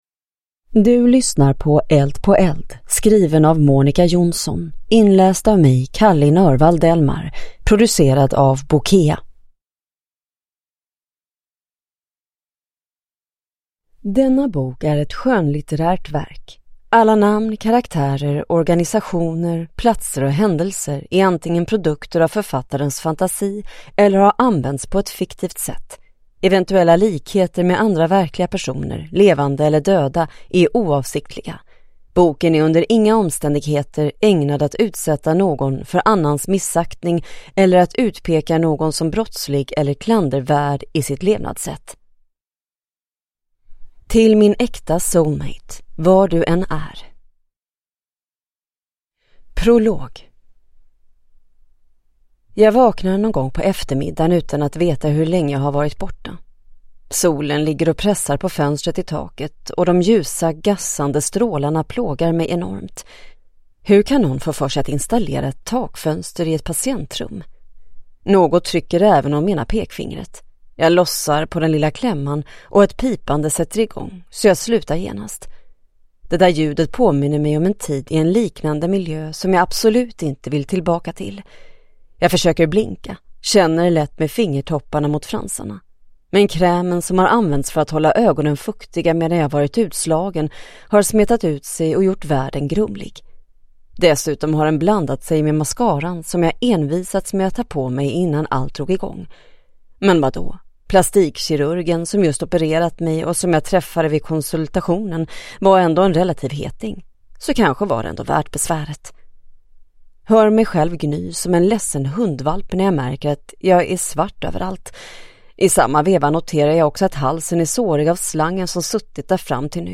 Eld på eld – Ljudbok